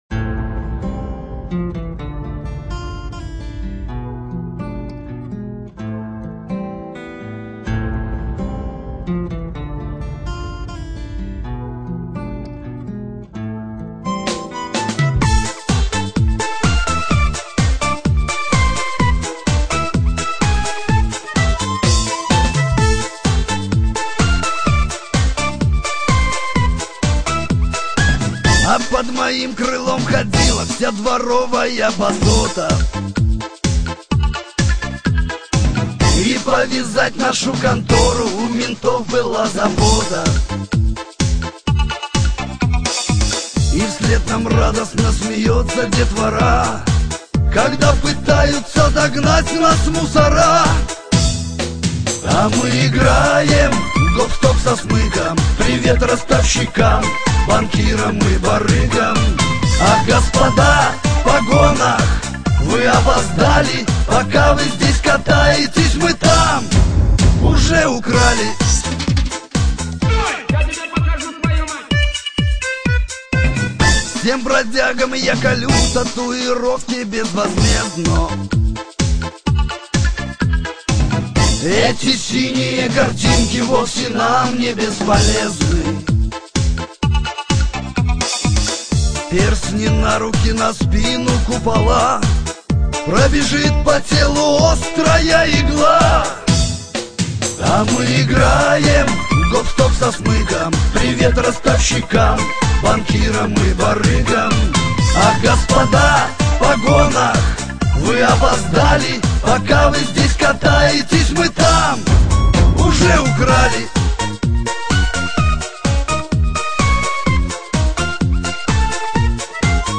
музыка шансон